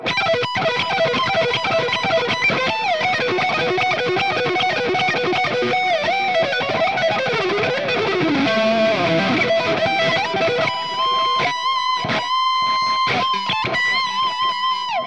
Lead
RAW AUDIO CLIPS ONLY, NO POST-PROCESSING EFFECTS
Hi-Gain